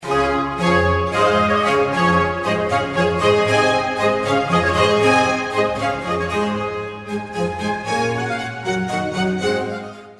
formal